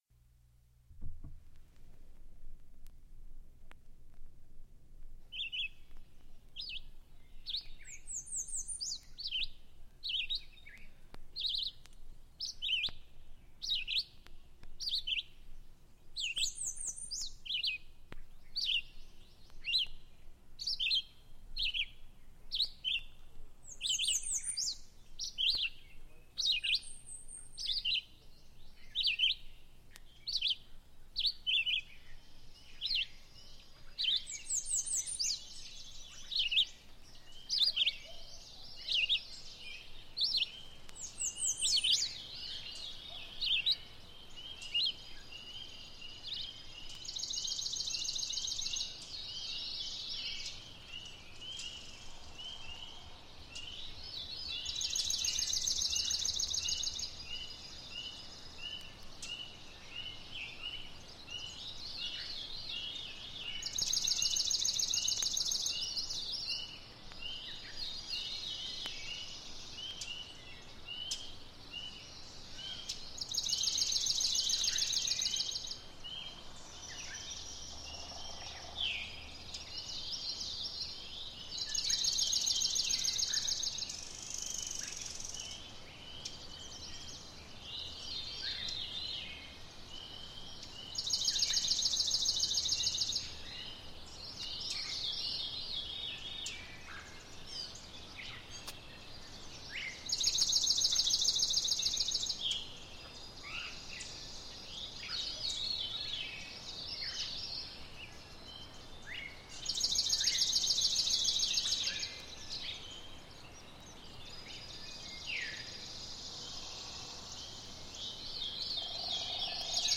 The beautiful Dawn Chorus intro to WGBH’s “Morning Pro Musica” classical music radio show
It began with the sounds of birds for about 5 minutes, then a slow crossfade into a specific piece of classical music.
For years, I happily woke to the sounds of birds and Handel.
From there it was easy enough to take one of those tracks and crossfade it into Handel.
morning-pro-musica-intro-birds-and-music.mp3